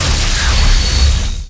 plasma_start.wav